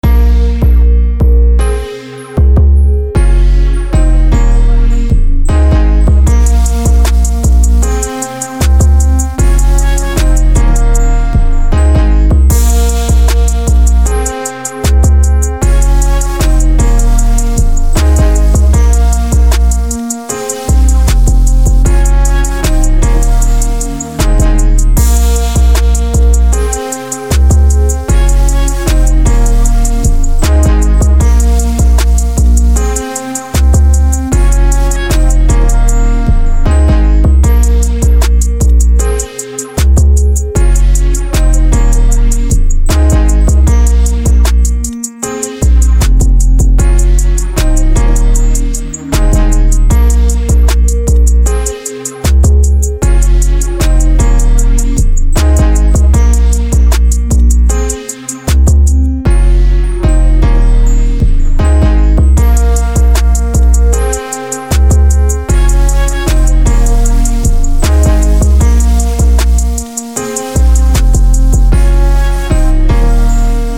Hip Hop Industry Type Rap Beat